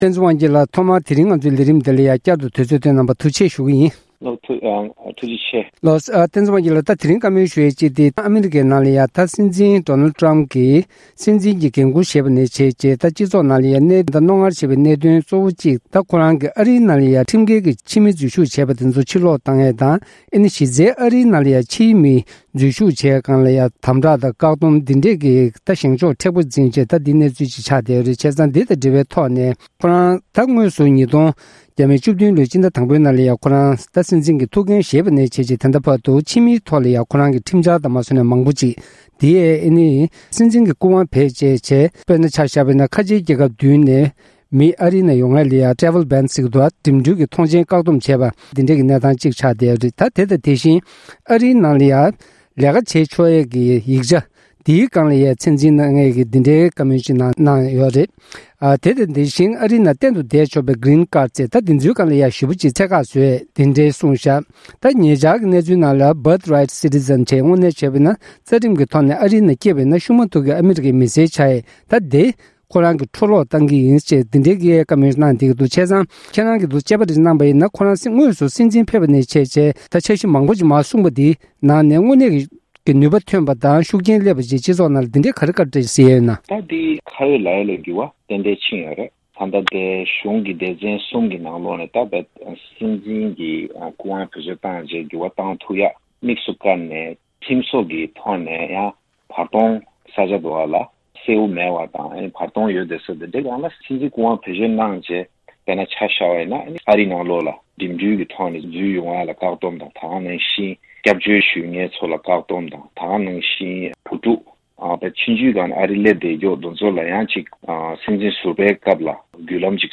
གནས་འདྲི་ཞུས་པ་གསན་རོགས་གནང་༎